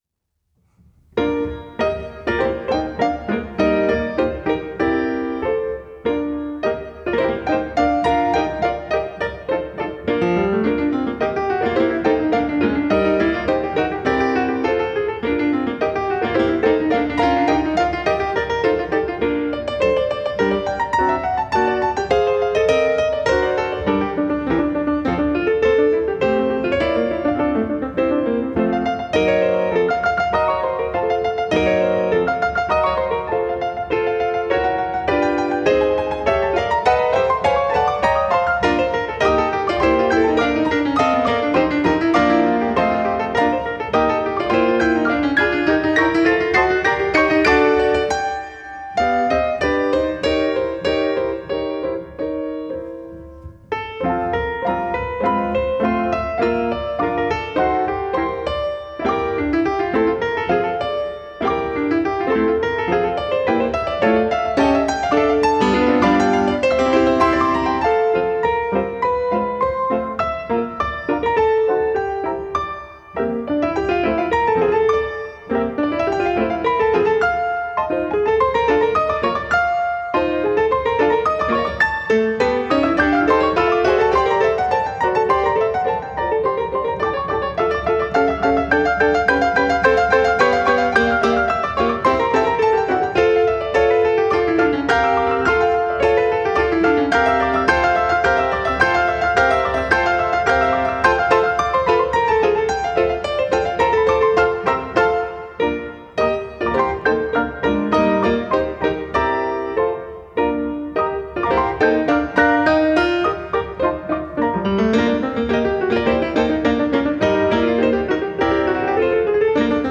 Two pianos
a live recording of a two piano recital
The combined sound of two concert grand pianos requires careful planning.